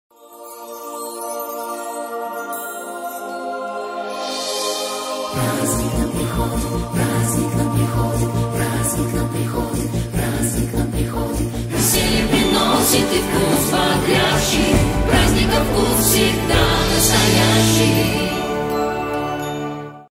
Мелодия в тему